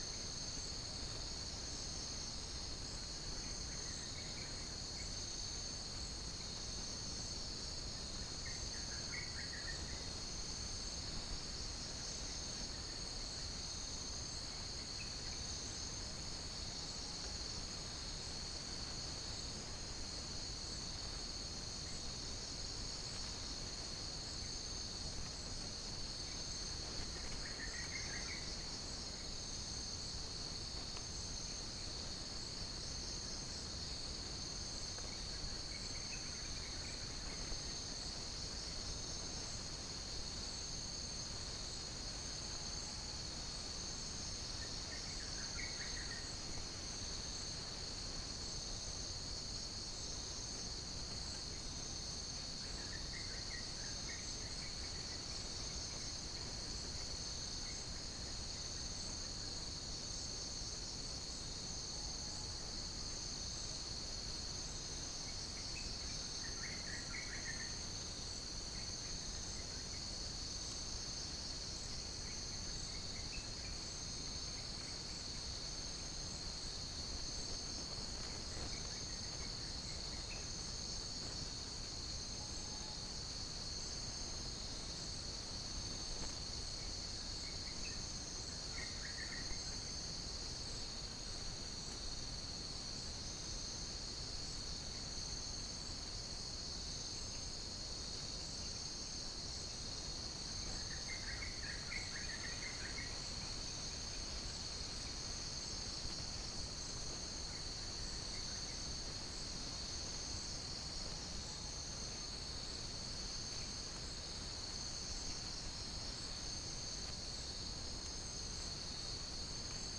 Spilopelia chinensis
Gallus gallus
Geopelia striata
Pycnonotus goiavier
Halcyon smyrnensis
Pycnonotus aurigaster
Dicaeum trigonostigma